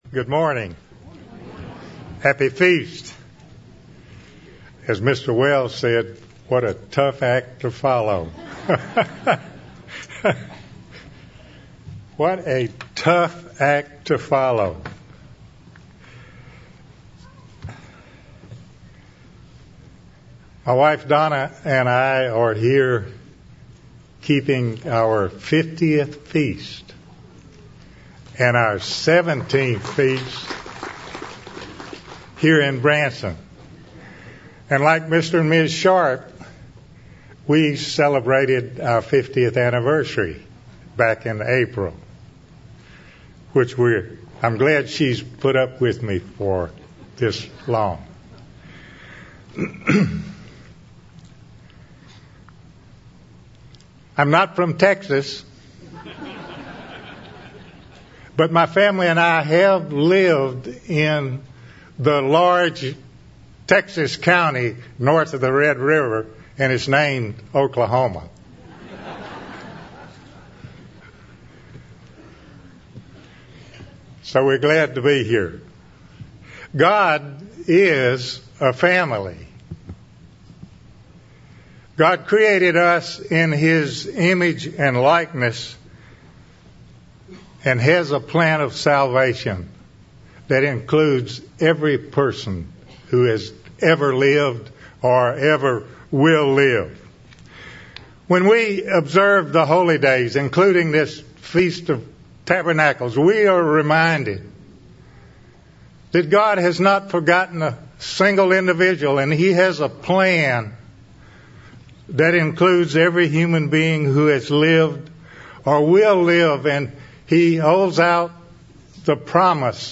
This sermon was given at the Branson, Missouri 2018 Feast site.